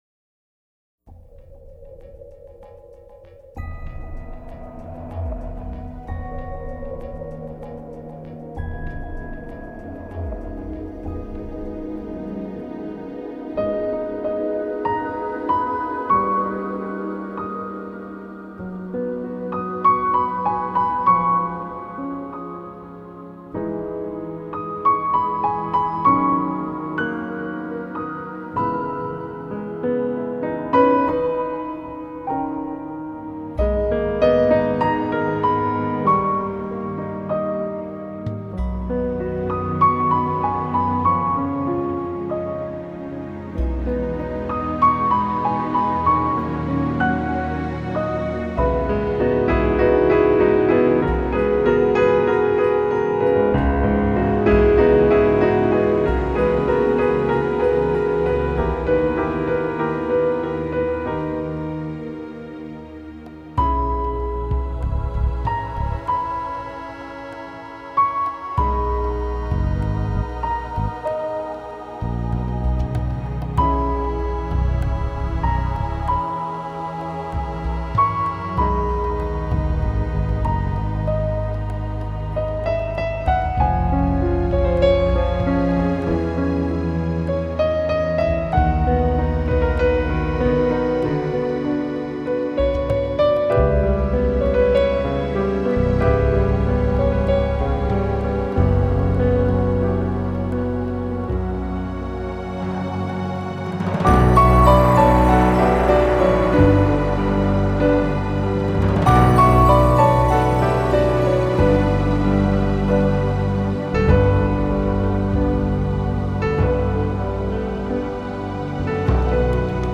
New Age
这个铁三角组合为全碟带来柔和流畅的爵士乐曲，浸出淡淡混合了经典、蓝调和传统日本音乐的曲韵。